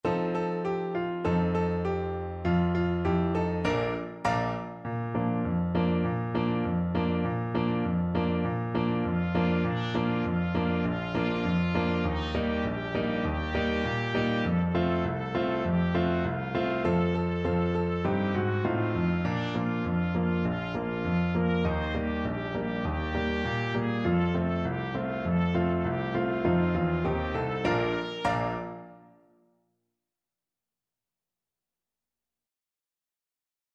Traditional Trad. Knees Up, Mother Brown Trumpet version
Trumpet
2/2 (View more 2/2 Music)
Bb major (Sounding Pitch) C major (Trumpet in Bb) (View more Bb major Music for Trumpet )
Raucous, two in a bar =c.100
Traditional (View more Traditional Trumpet Music)